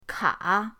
ka3.mp3